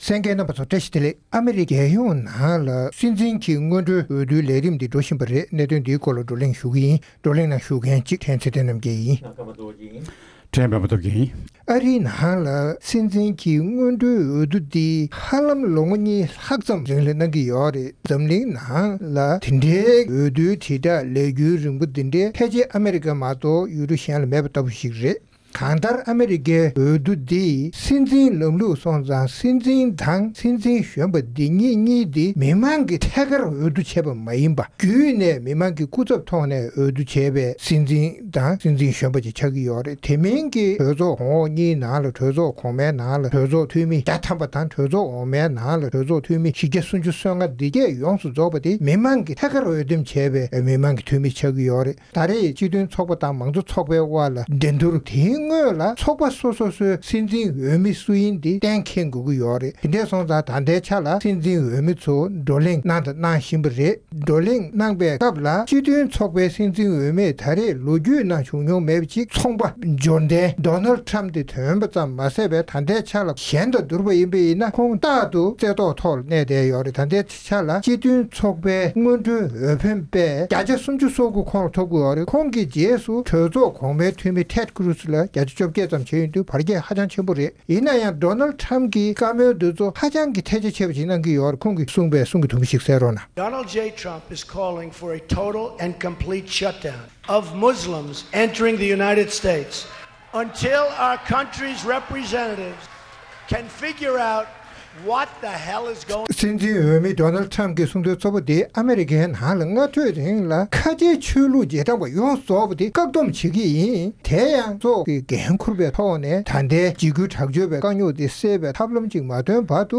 ༄༅༎ཐེངས་འདིའི་རྩོམ་སྒྲིག་འགན་འཛིན་གྱི་དཔྱད་གླེང་གི་ལེ་ཚན་ནང་ཨ་མེ་རི་ཀའི་ཕྱི་ལོ་༢༠༡༦ལོའི་རྒྱལ་ཡོངས་སྲིད་འཛིན་གྱི་སྔོན་འགྲོའི་འོས་བསྡུའི་ལས་རིམ་འགྲོ་བཞིན་པའི་ཐོག་ལ་རྩོམ་སྒྲིག་འགན་འཛིན་རྣམས་ནས་དཔྱད་ཞིབ་གནང་བའི་ལས་རིམ་ཞིག་གསན་རོགས༎